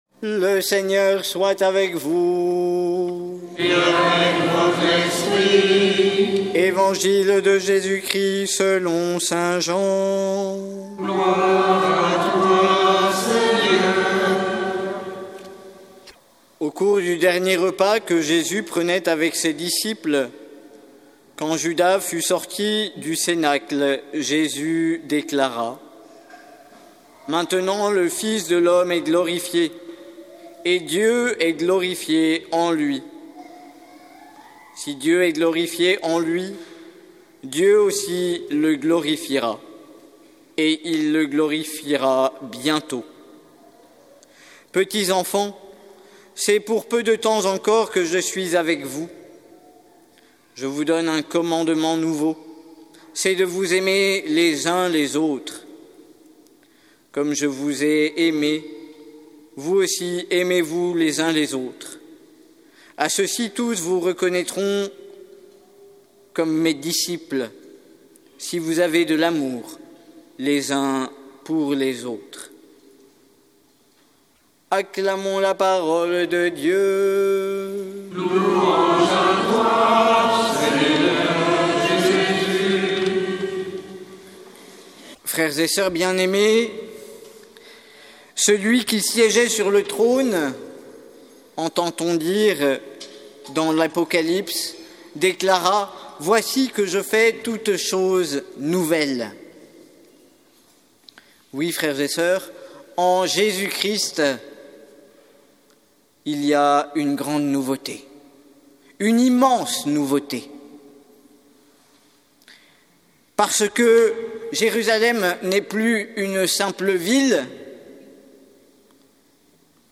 Évangile de Jésus Christ selon saint Jean avec l'homélie